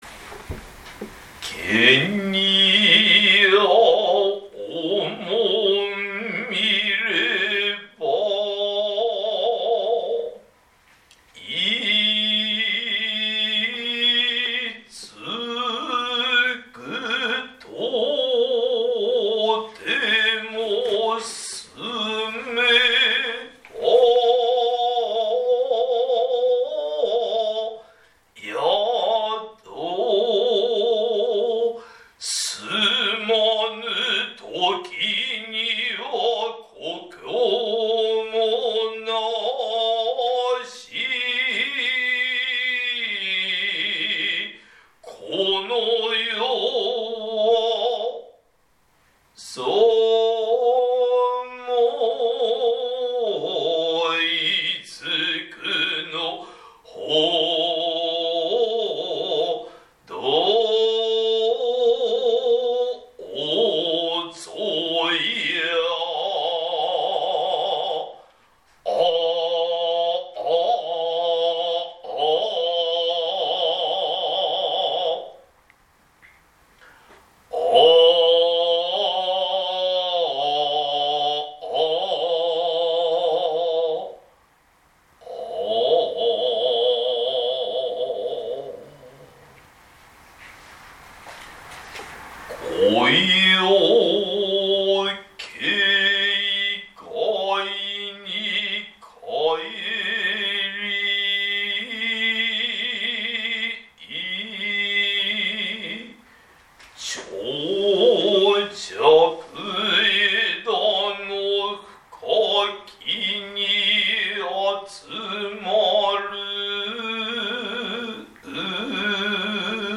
お稽古 謡